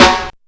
• Smooth Bottom End Snare Drum Sound A# Key 173.wav
Royality free snare drum sound tuned to the A# note. Loudest frequency: 1848Hz
smooth-bottom-end-snare-drum-sound-a-sharp-key-173-TSr.wav